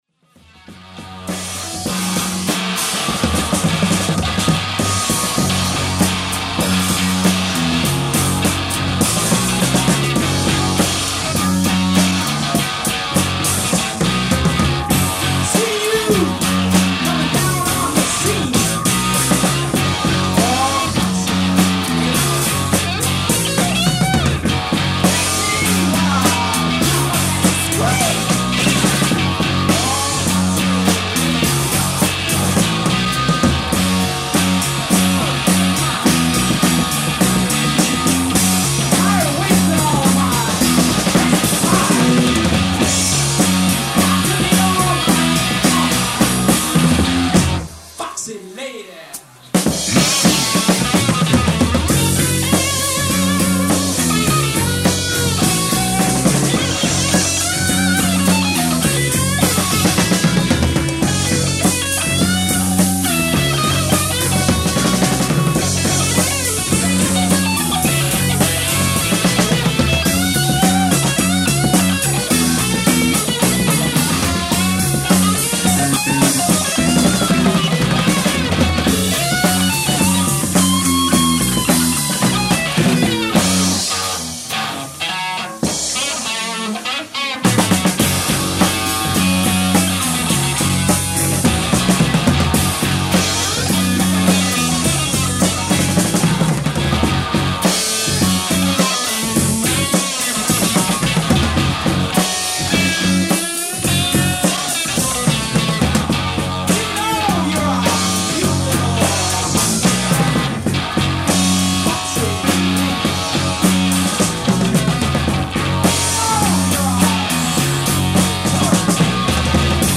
bass
drums
guitar, lead vocal